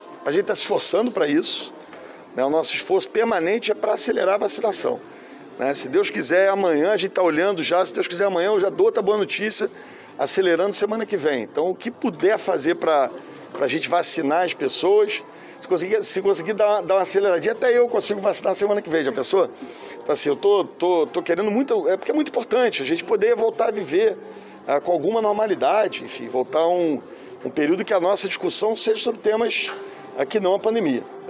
O prefeito Eduardo Paes confirmou que o calendário de vacinação no Rio vai ser acelerado. A declaração foi feita na saída de um evento em um Hotel de Copacabana e depois Ratificada no twitter oficial dele.